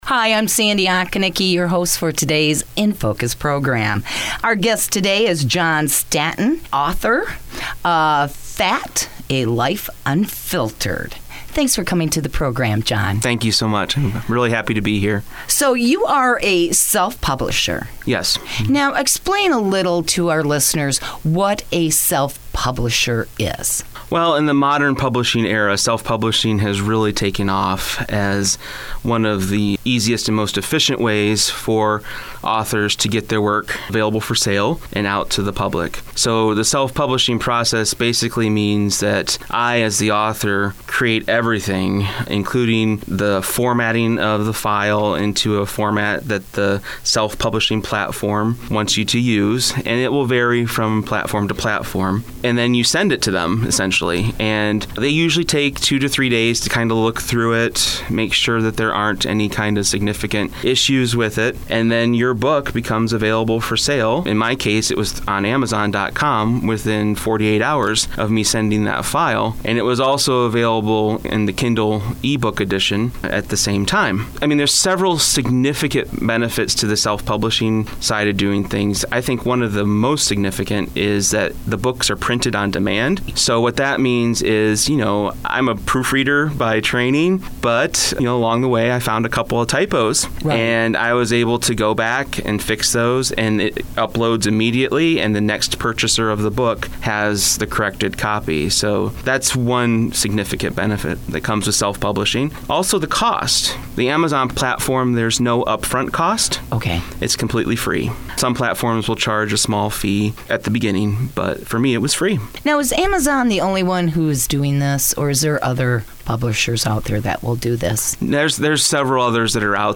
Interview: “In Focus New Program” Topic: Self-Publishing and “FAT: A Life Unfiltered” Z92.5 The Castle, Owosso, MI Listen